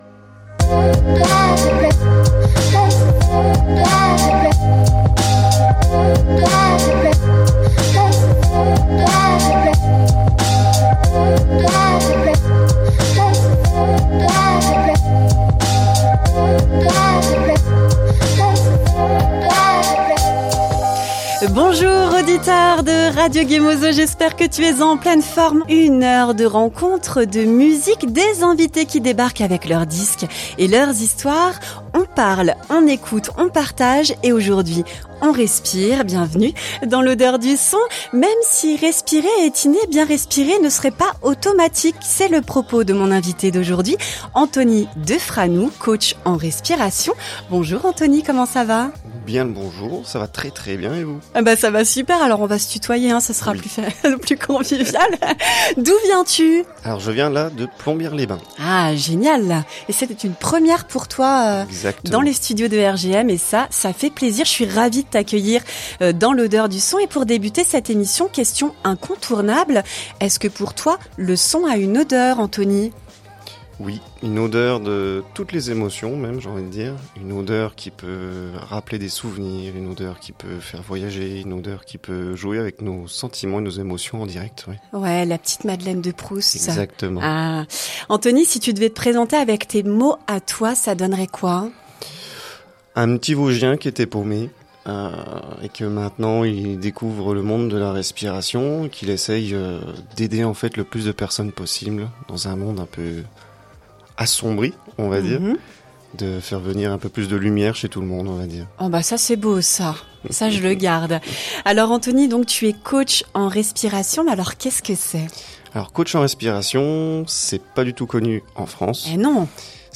pour une conversation passionnante autour du souffle et du bien-être